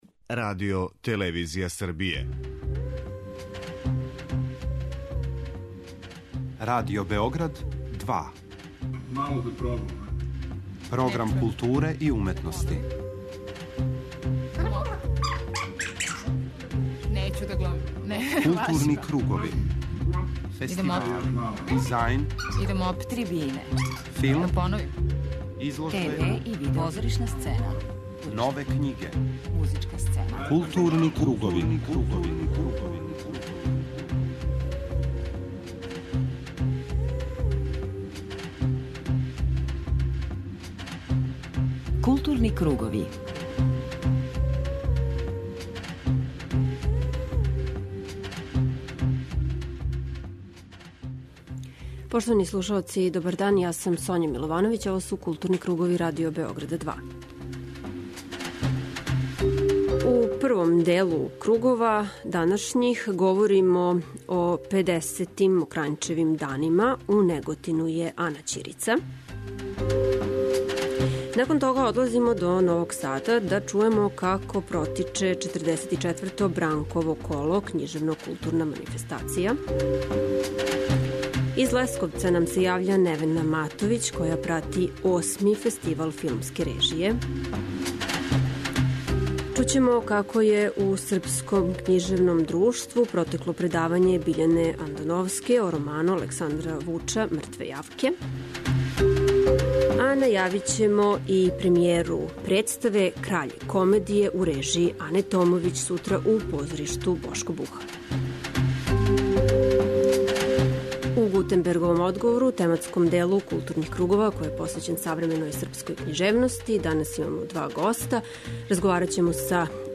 преузми : 52.91 MB Културни кругови Autor: Група аутора Централна културно-уметничка емисија Радио Београда 2.